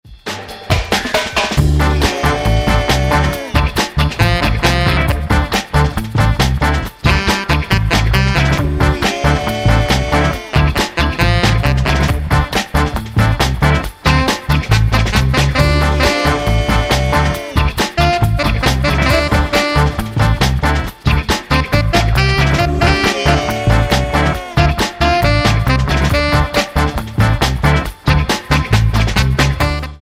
their first long player which is deeply rooted in Soul.